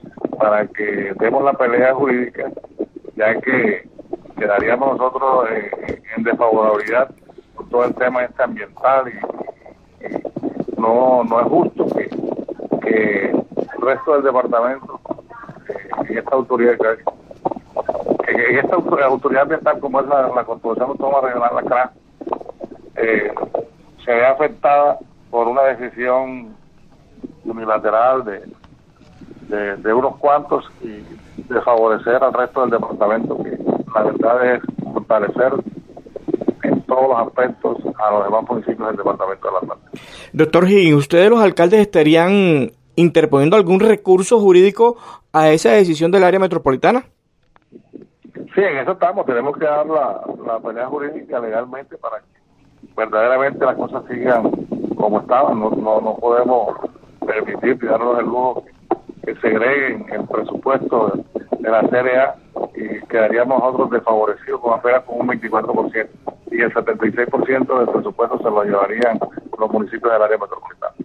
VOZ-CARLOS-HIGGINS-AMBIENTAL.mp3